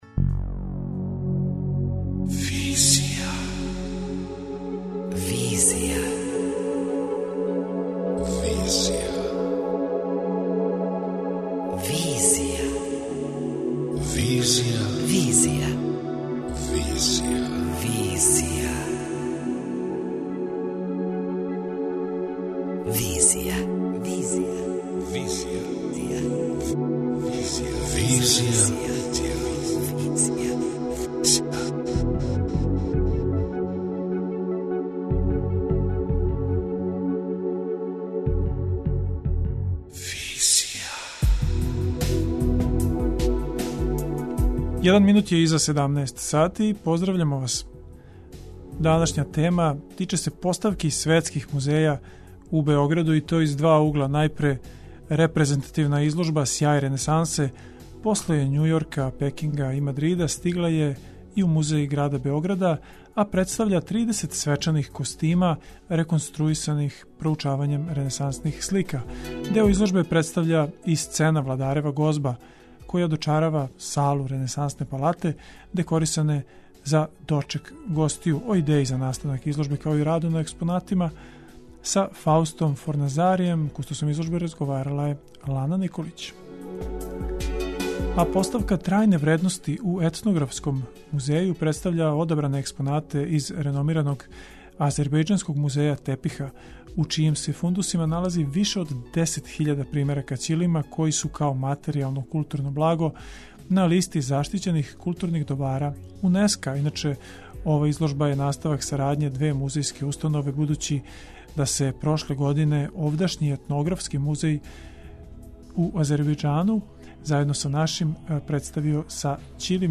преузми : 27.36 MB Визија Autor: Београд 202 Социо-културолошки магазин, који прати савремене друштвене феномене.